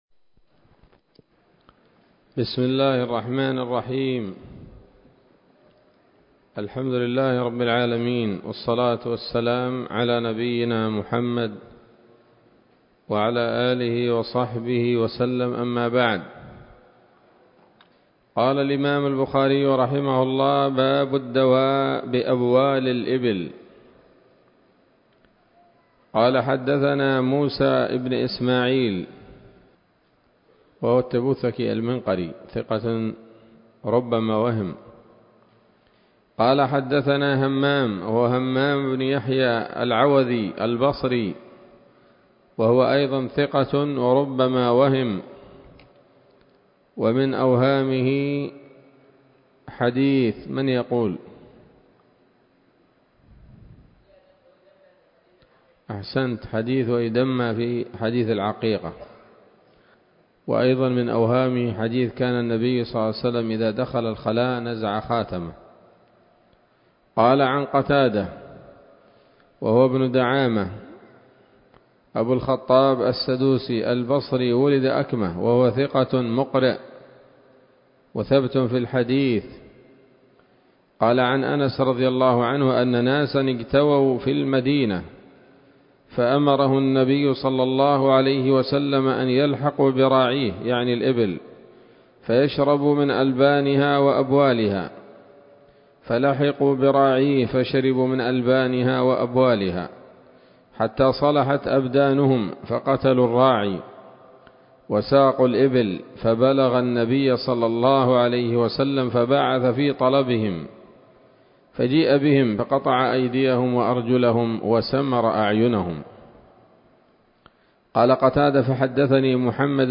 الدرس السادس من كتاب الطب من صحيح الإمام البخاري